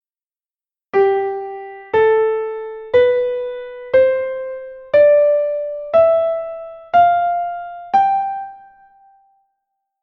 Facemos a escala de Sol normal, só coas notas:
Sol_M_sin_sost.mp3